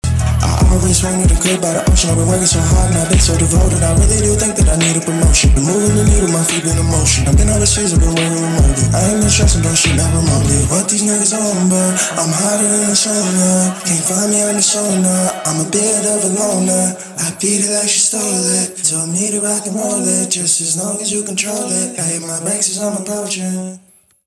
хип-хоп
басы